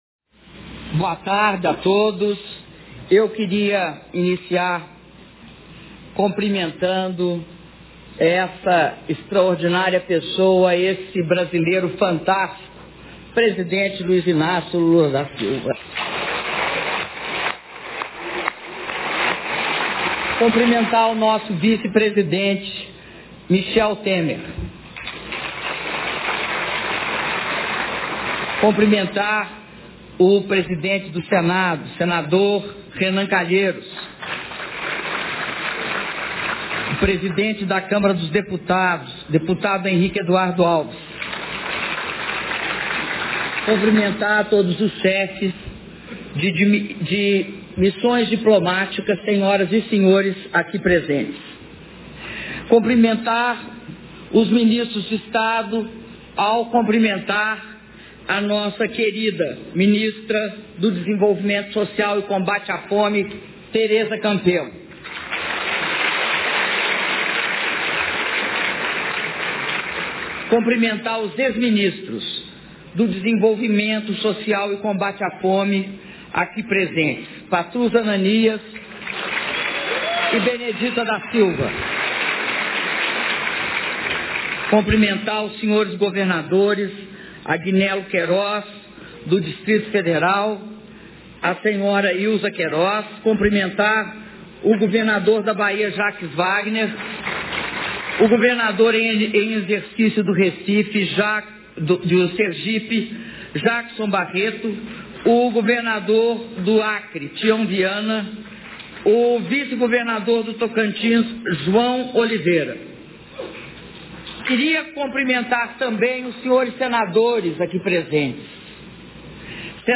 Áudio do discurso da Presidenta da República, Dilma Rousseff, na celebração dos 10 anos do Programa Bolsa Família - Brasília/DF